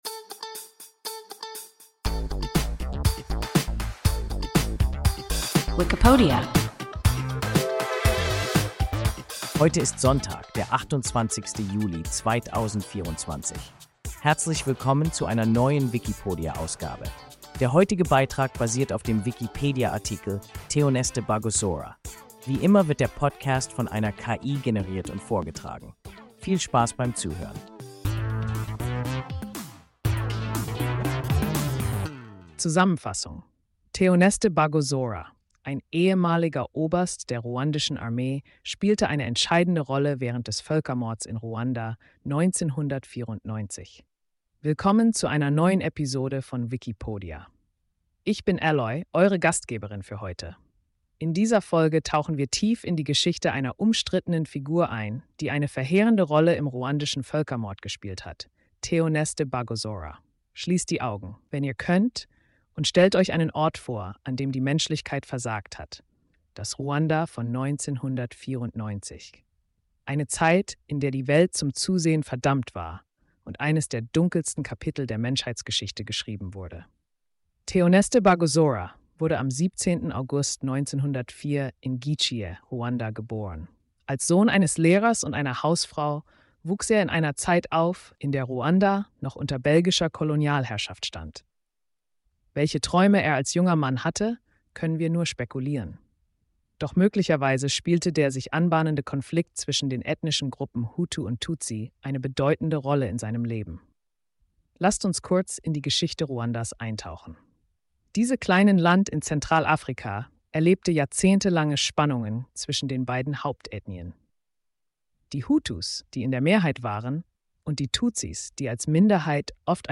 Théoneste Bagosora – WIKIPODIA – ein KI Podcast